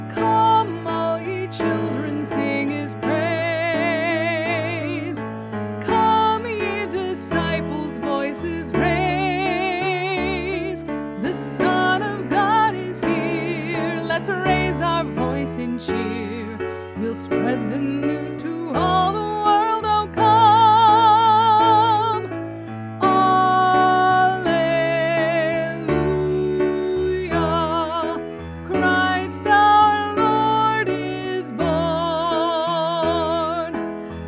choral song arrangements